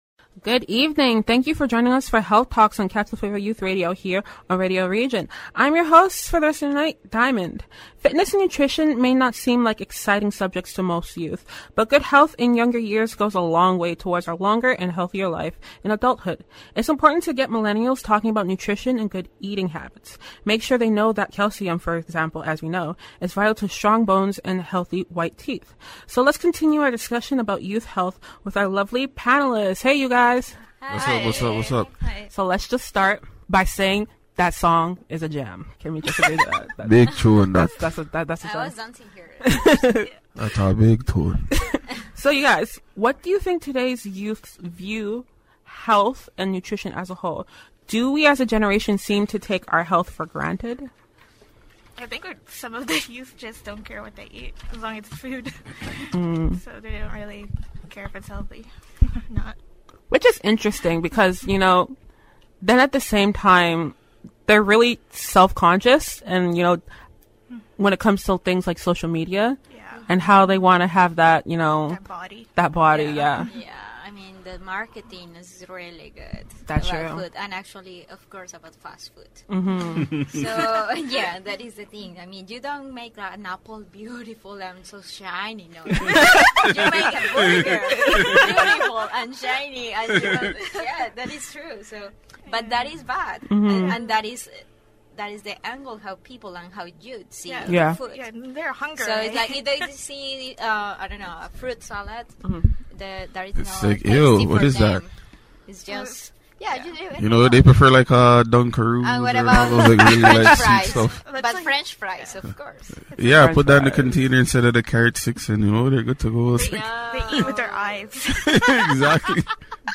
In discussing these questions, the panelists shared their own stories about healthy lifestyles and how to stay in shape but still have fun.